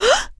Звуки человеческого испуга
Короткий звук испуганной женщины
korotkij_zvuk_ispugannoj_zhenshini_uec.mp3